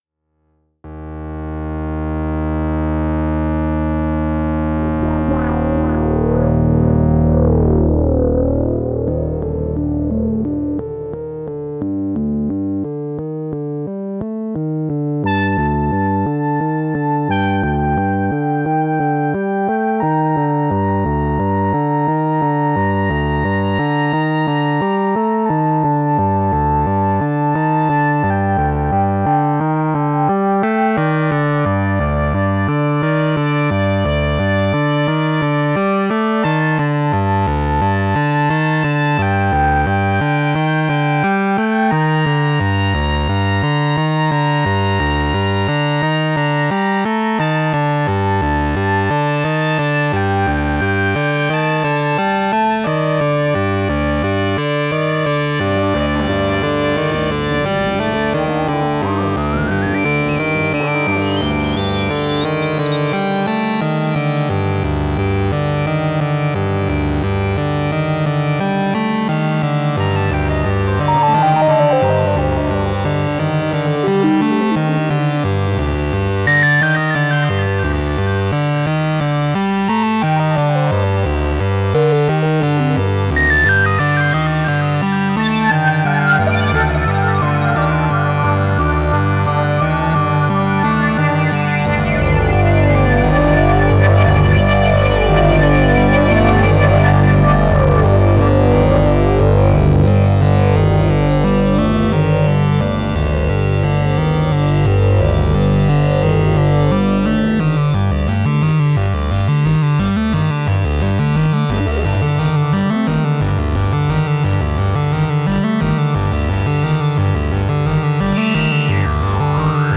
Slow build ambient piece.